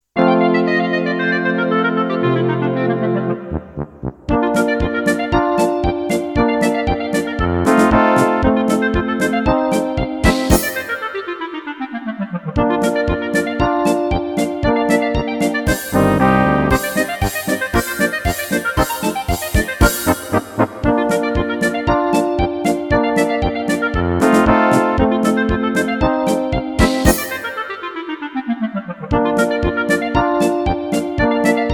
Rubrika: Národní, lidové, dechovka
- polka